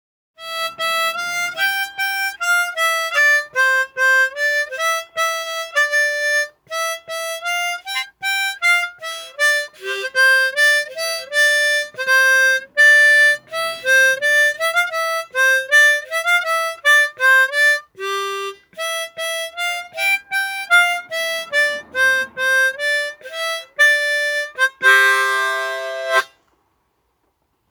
At the end of his speech he wished everyone in the chamber a happy Easter, thanked them for their company over the five years and then pulled a mouth organ out of his pocket and gave a rendition of Ode to Joy.
As for me, I felt inspired enough to rush upstairs, gather my own mouth organ from the draw by my bedside and belt out my version of the tune myself.
Hopefully you are as well by my poorer rendition.